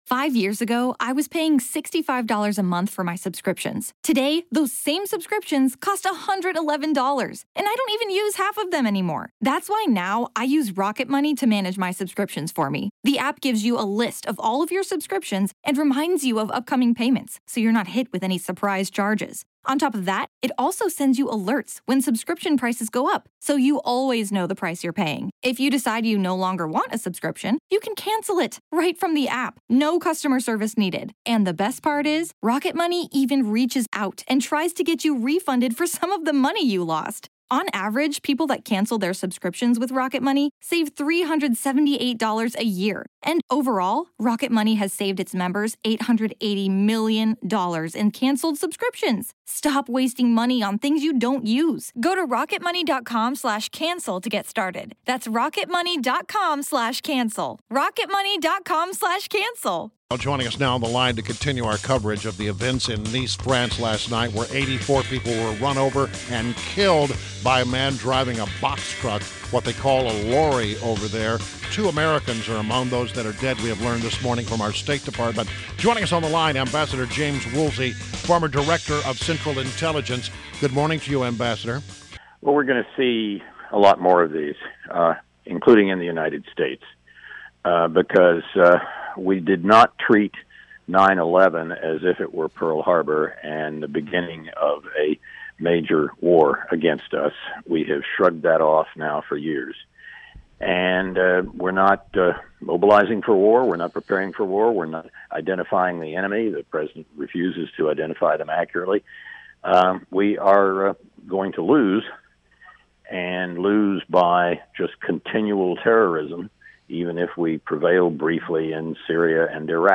WMAL Interview - James Woolsey - 07.15.16